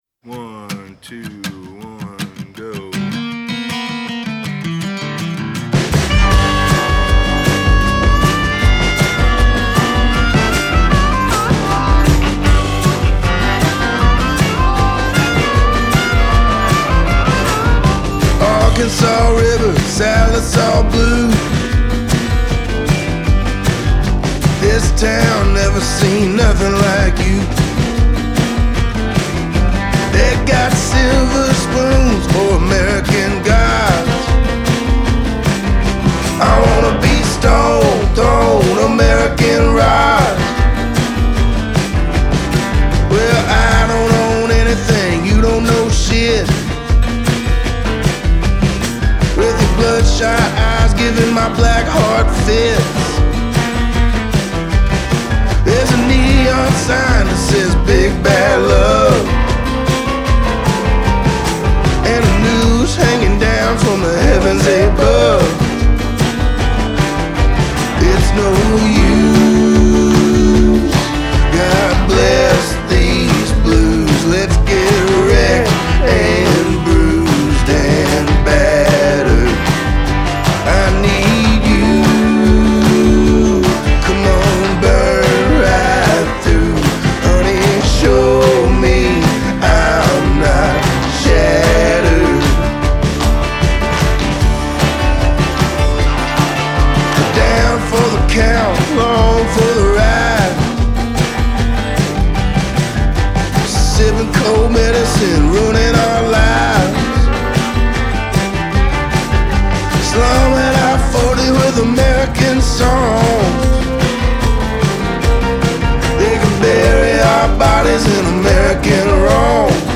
bustling country-blues rockers